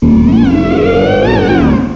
cry_not_gourgeist_super.aif